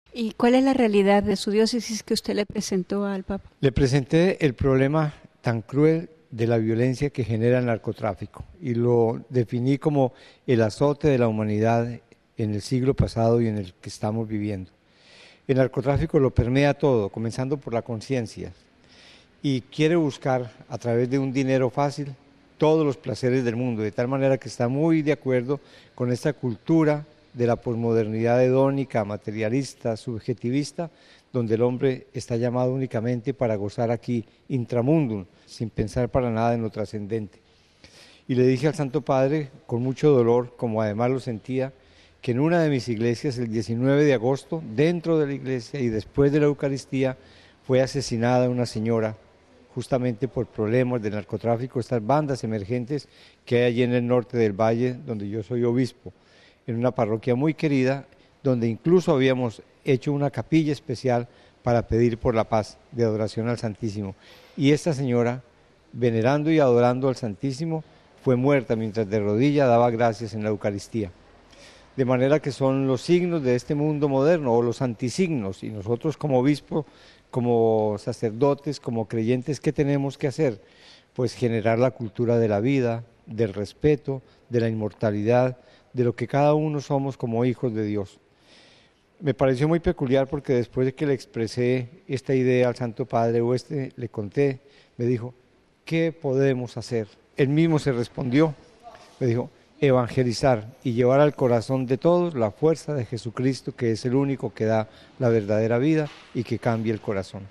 Al preguntarle sobre la realidad que vive Cartago nos dijo que sufre el problema tan cruel de la violencia, que general el narcotráfico.